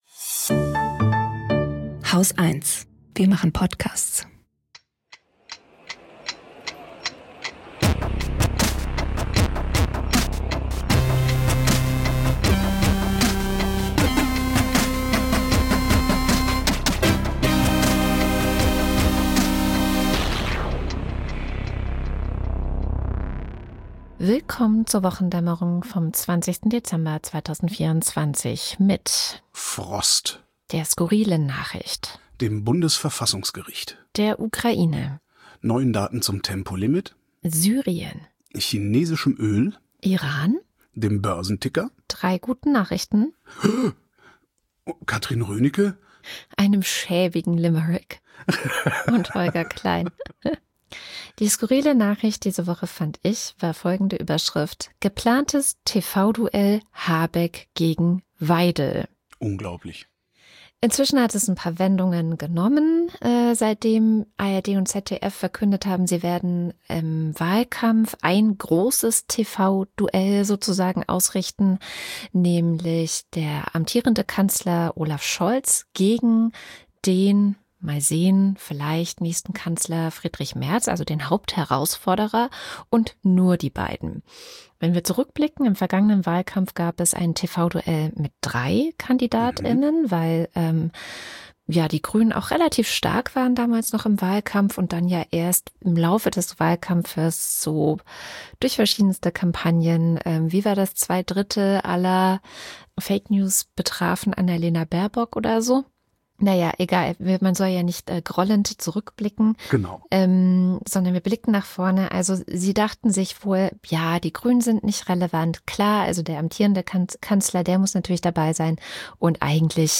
Gesprächspodcast
News Talk